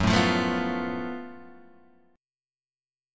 F#mM11 chord